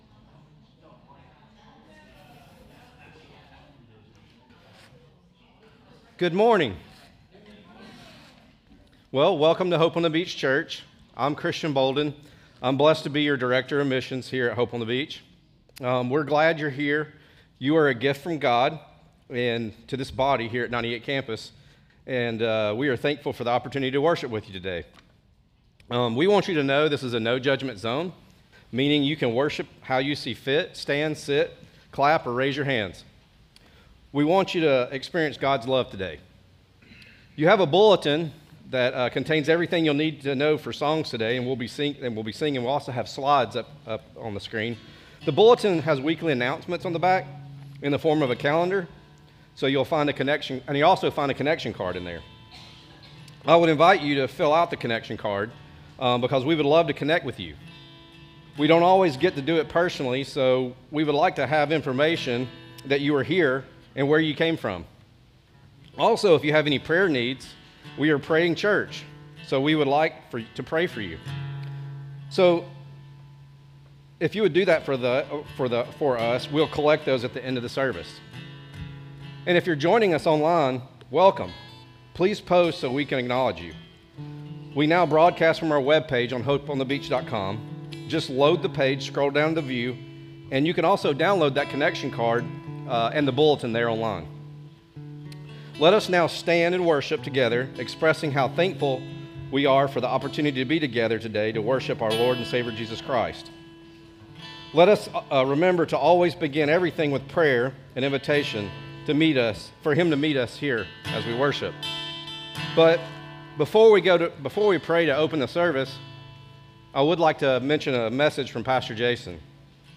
SERMON DESCRIPTION This message from Hosea 1–2 reveals a God who speaks through lived sacrifice.